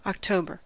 Ääntäminen
Vaihtoehtoiset kirjoitusmuodot (vanhentunut) Octobr (vanhentunut) Octobre Ääntäminen US : IPA : [ɑkˈtoʊ.bɚ] UK : IPA : /ɒkˈtəʊ.bə/ Tuntematon aksentti: IPA : /ɑkˈtoʊbəɹ/ Lyhenteet ja supistumat Oct.